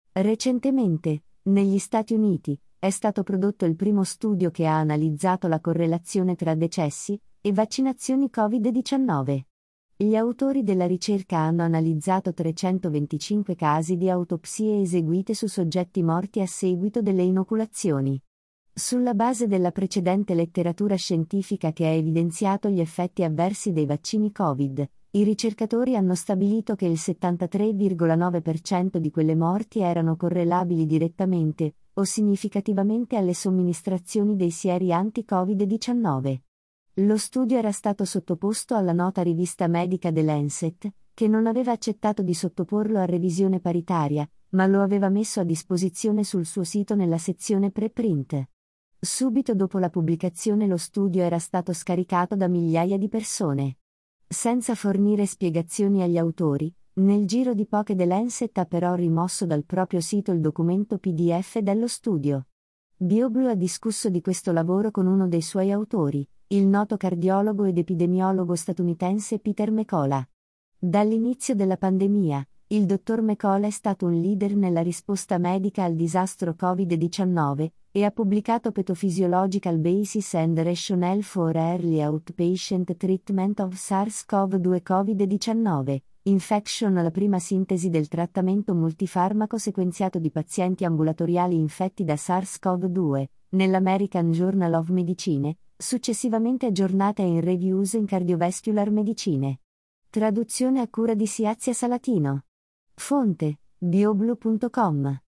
Byoblu ha discusso di questo lavoro con uno dei suoi autori: il noto cardiologo ed epidemiologo statunitense Peter McCullough.